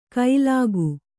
♪ kailāgu